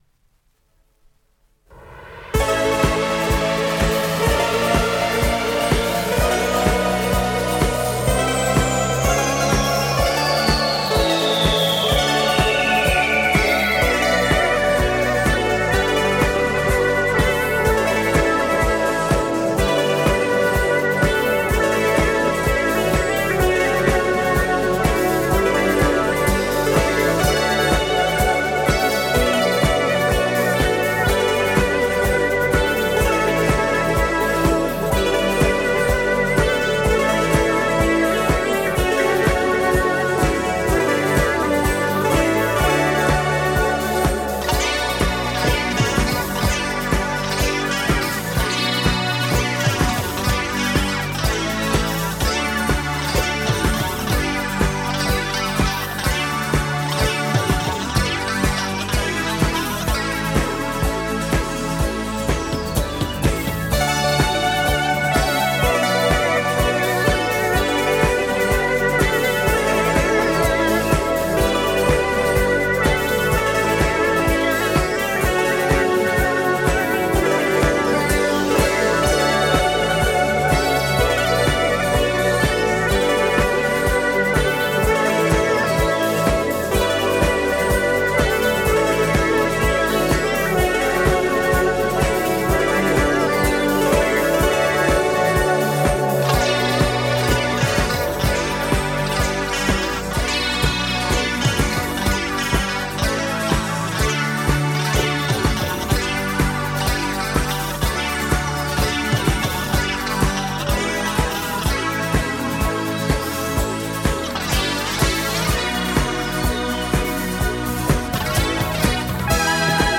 Жанр: Electronic